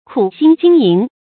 苦心經營 注音： ㄎㄨˇ ㄒㄧㄣ ㄐㄧㄥ ㄧㄥˊ 讀音讀法： 意思解釋： 苦心：用心勞苦。